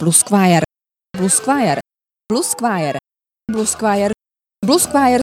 2) nejsme ani „BLUSKVAJER“, což by mohlo přinejlepším evokovat poněkud jiný žánr (blue squire „modré páže“?) či jiné hudební těleso (blues choir „bluesový chór“?) – navzdory pětici opakování opravdu ne: